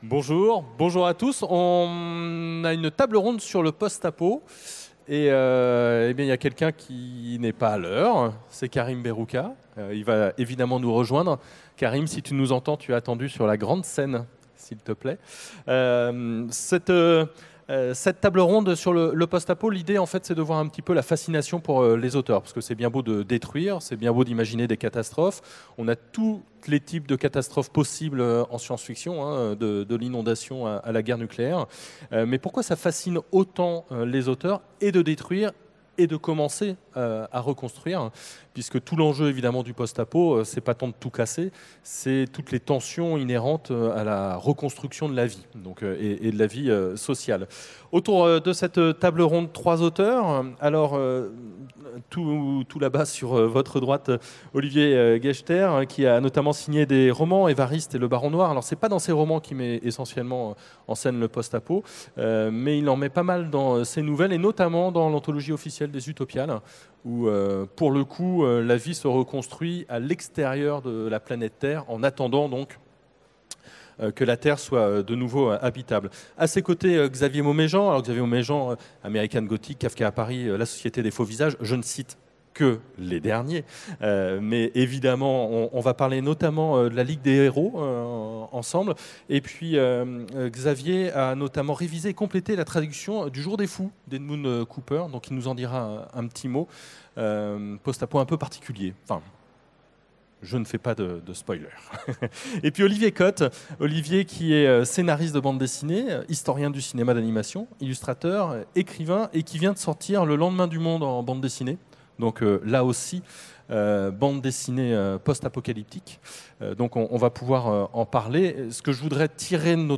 Utopiales 2017 : Conférence Le Post-apocalyptique : quelle fascination pour les auteurs ?